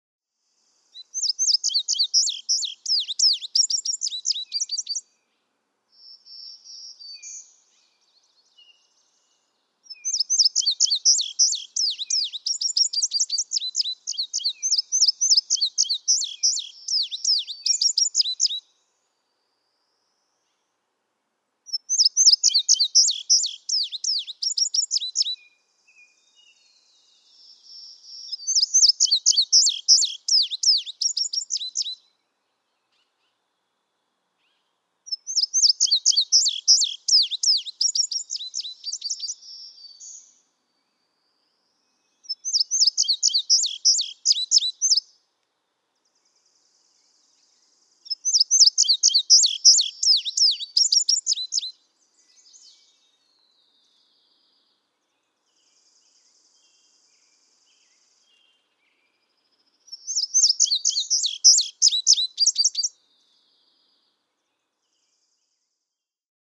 Example 1. Indigo bunting: One song from each of two neighboring indigo buntings with similar songs (♫200).
Quabbin Park, Ware, Massachusetts.
♫203, ♫204—longer recordings from those two neighbors
203_Indigo_Bunting.mp3